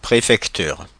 Ääntäminen
Synonyymit département hôtel du département Ääntäminen Paris Tuntematon aksentti: IPA: /pʁe.fɛk.tyʁ/ Haettu sana löytyi näillä lähdekielillä: ranska Käännös Substantiivit 1. prefecture Suku: f .